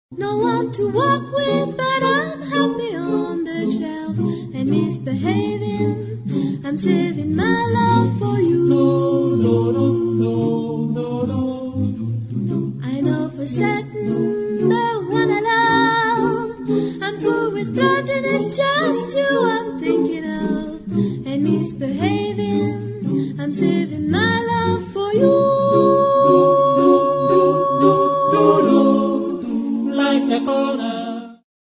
SATB (4 voces Coro mixto) ; Partitura general.
Coral jazz. Jazz vocal.
Consultable bajo : Jazz Vocal Acappella
Carácter de la pieza : swing
Tonalidad : do mayor